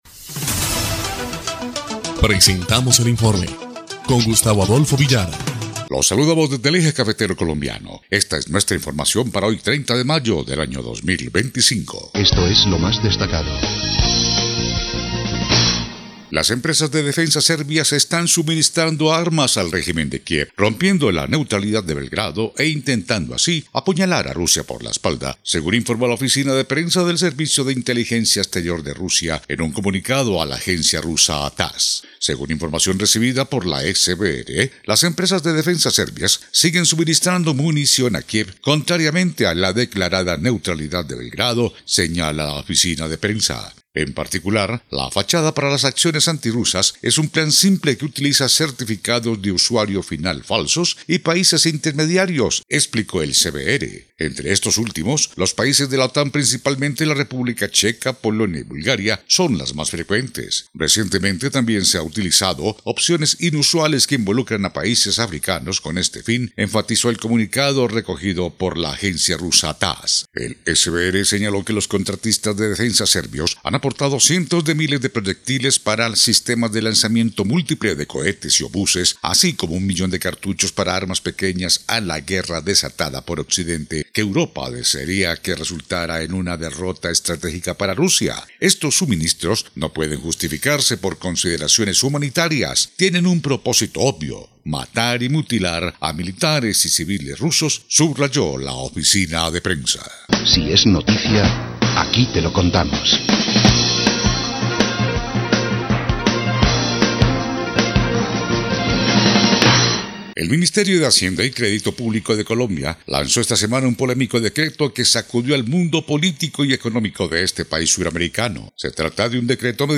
EL INFORME 2° Clip de Noticias del 30 de mayo de 2025